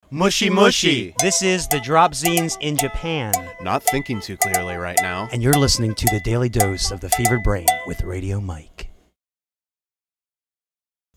The Dropzines' Radio Promos                  We have gotten  a number of requests lately to do Radio Promos for Radio Stations around the world that have been featuring The Dropzines' Music.